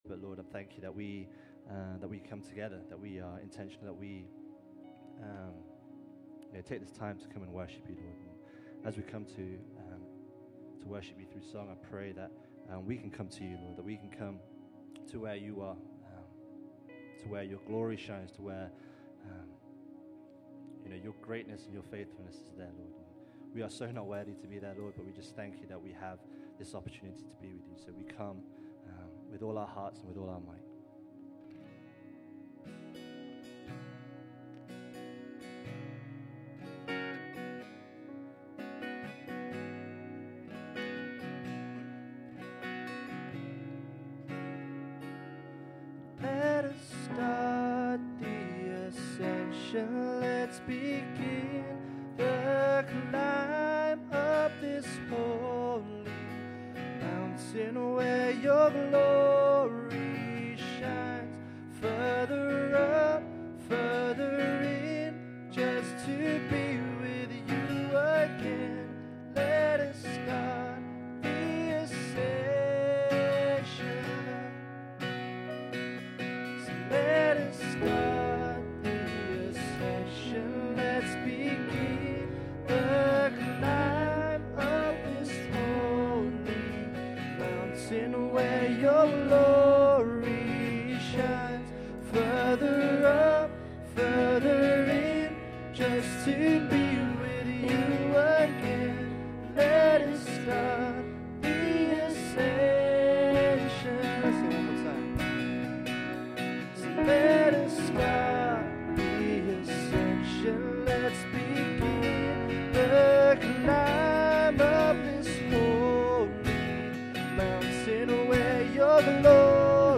Worship December 4, 2016